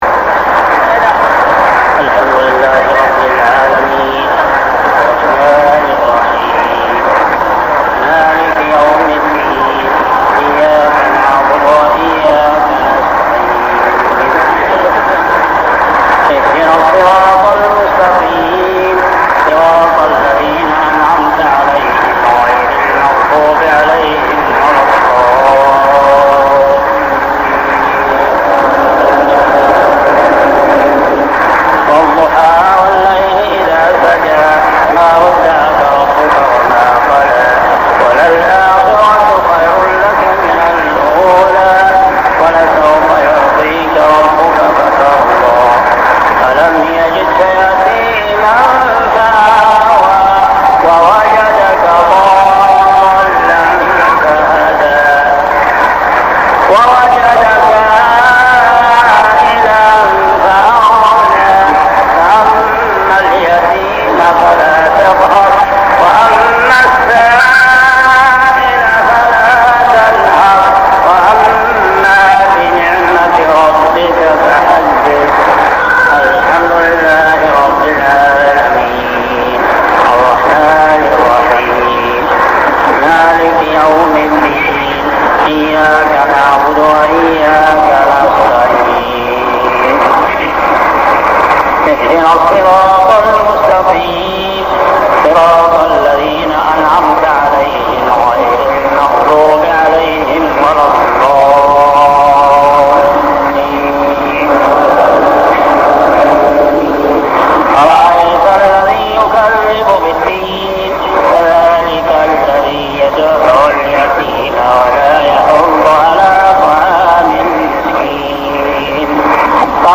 صلاة المغرب عام 1400هـ سورتي الضحى و الماعون كاملة | maghrib prayer Surah Ad-Duha and Al-Ma'un > 1400 🕋 > الفروض - تلاوات الحرمين